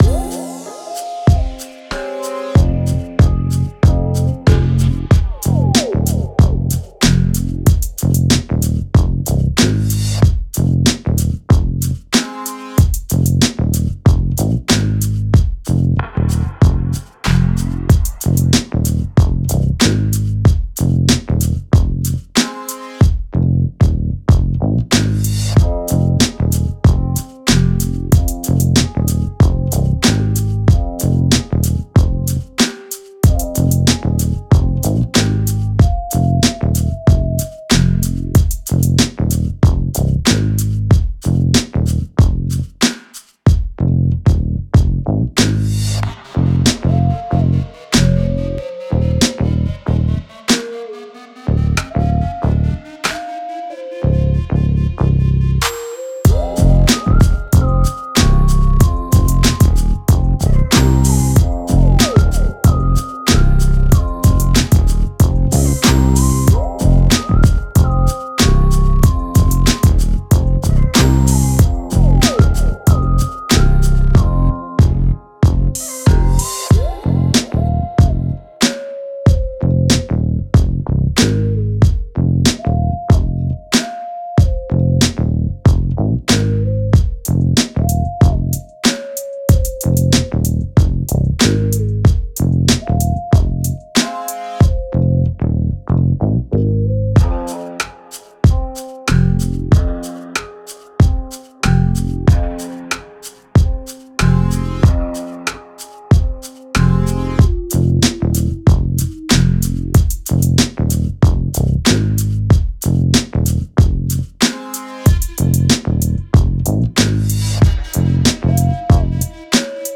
No vocals